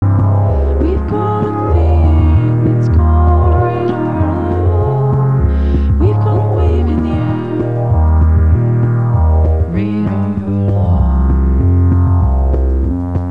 performing live on Casio with effects
haunting, humorous, and ethereal